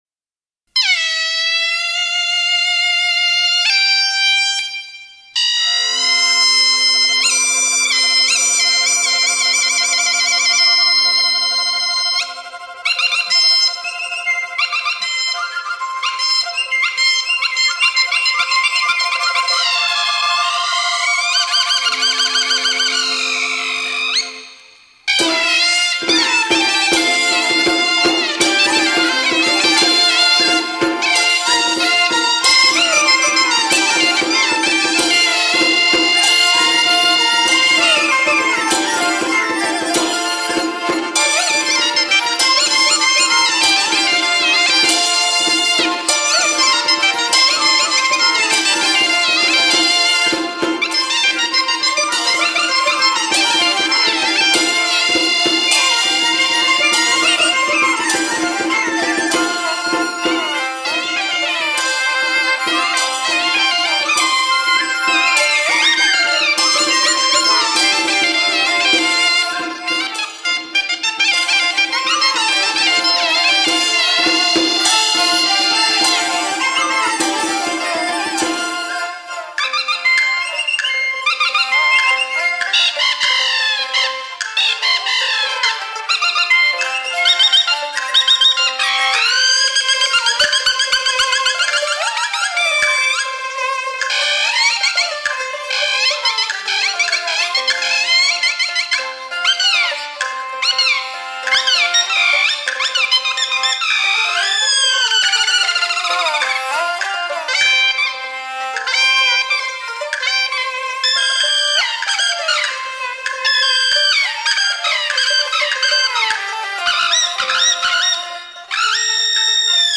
light]中国民乐国粹